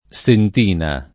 sentina [ S ent & na ] s. f.